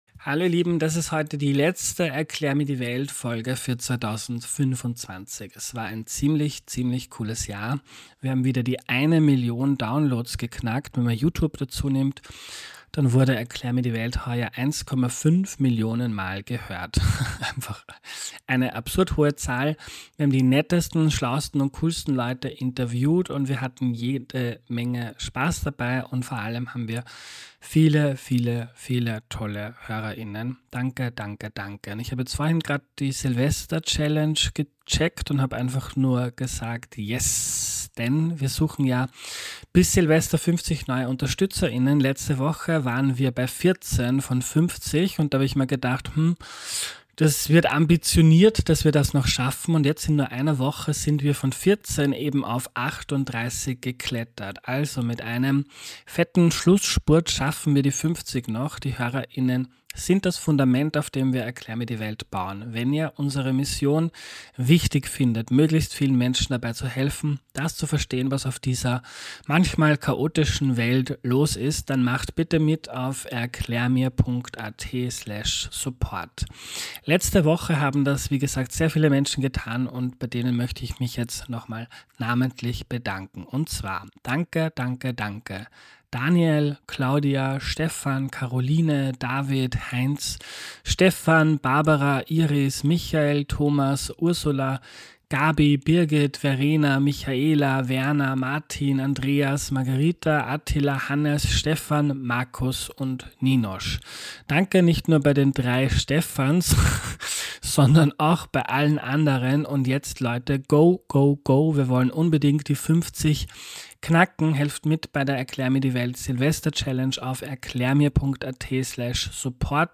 Was er auf seiner Reise erlebt und gelernt hat – ein sehr lustiges und zugleich inspirierendes Gespräch.